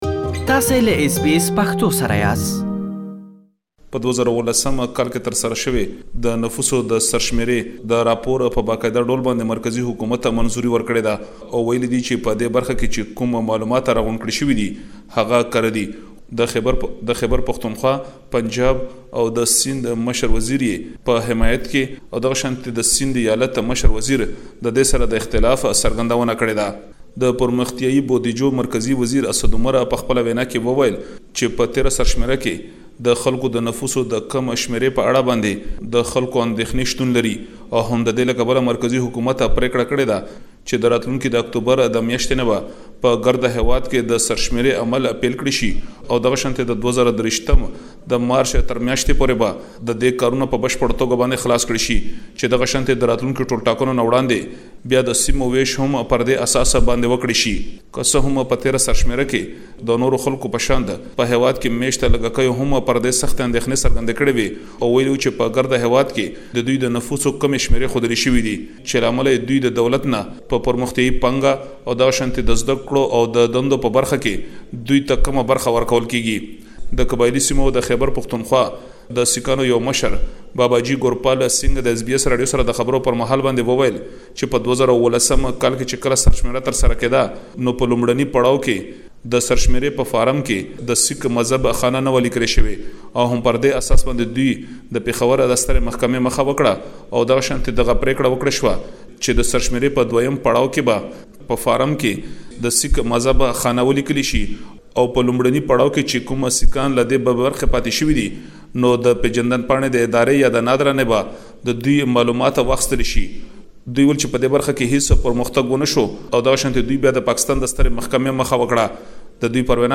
له پيښور څخه رپوټ لري چې دلته يې اوريدلی شئ.